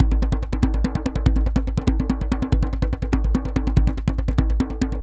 DJEM.GRV04.wav